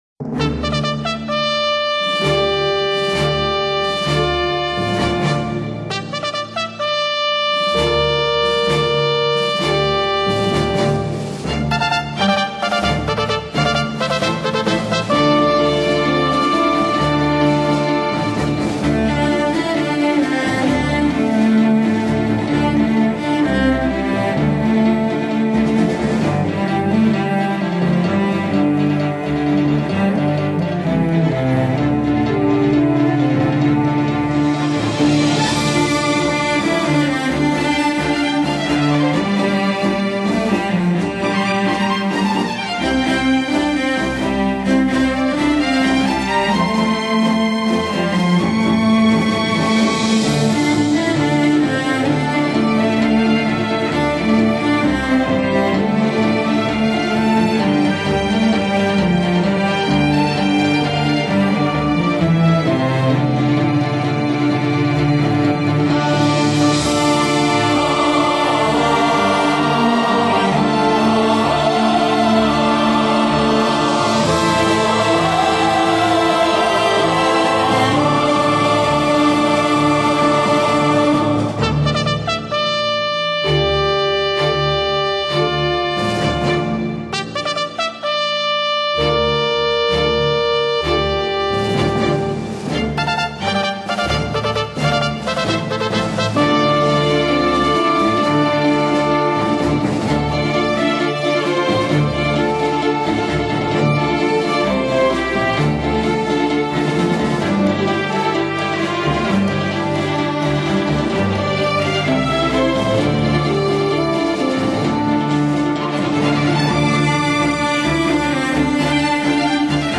大提琴